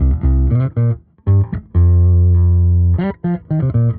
Index of /musicradar/dusty-funk-samples/Bass/120bpm
DF_JaBass_120-C.wav